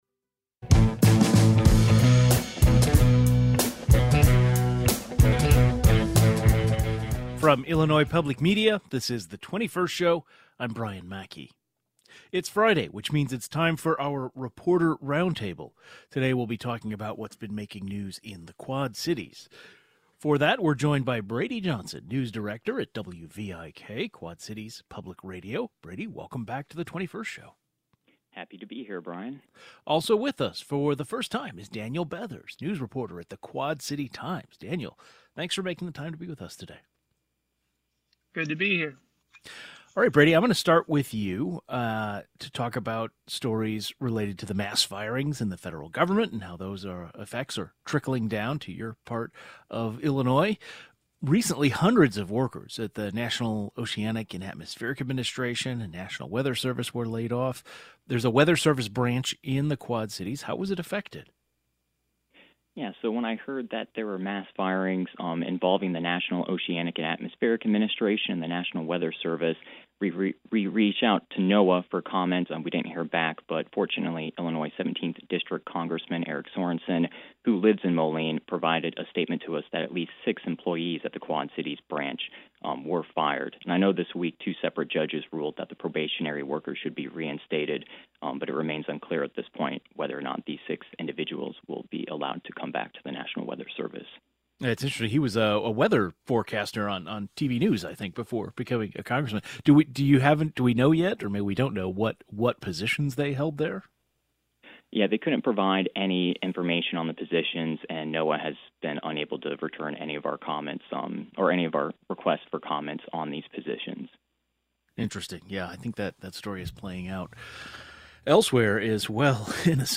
During our Friday Illinois Reporter Roundtable, we talked about stories in the Quad Cities, including a large rally from the local letter carriers union and a new dispensary and gas station coming to Rock Island meeting with some controversy.